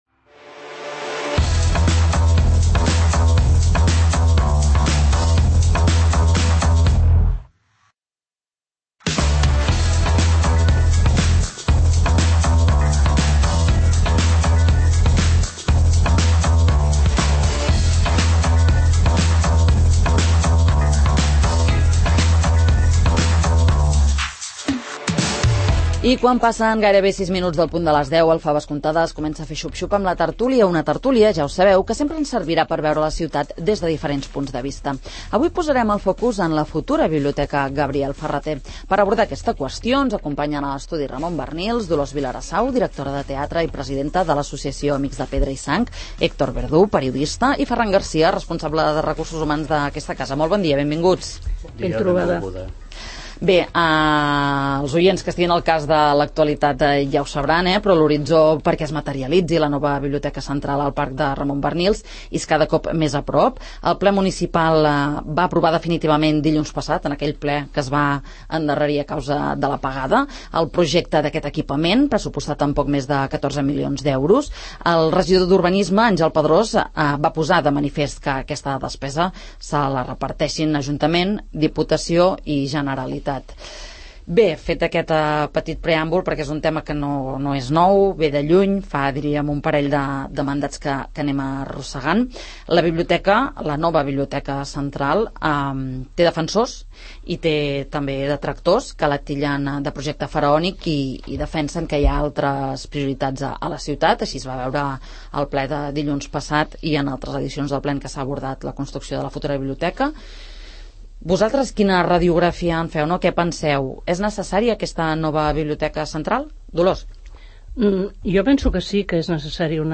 Com hauria de ser la futura biblioteca Gabriel Ferrater? En parlem a la tert�lia del 'Faves comptades'